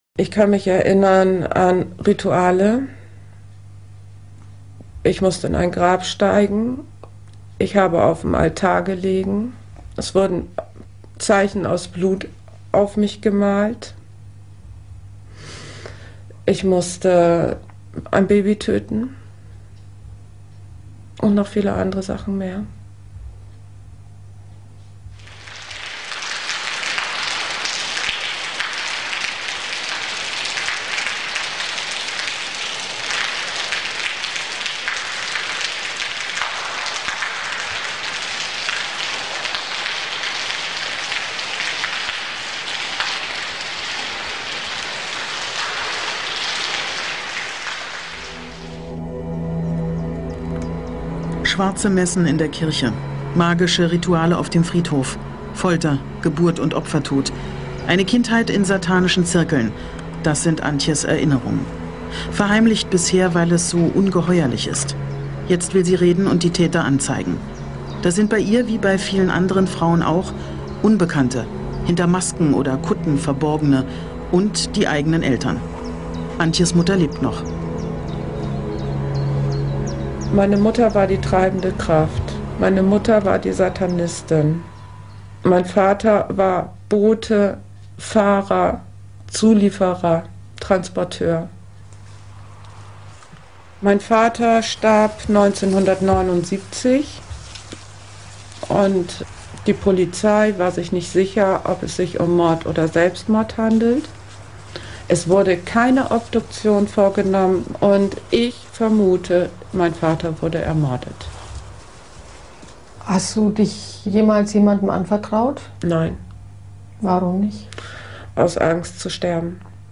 Dokumentarfilm
sprechen Sektenopfer über ihre Misshandlungen.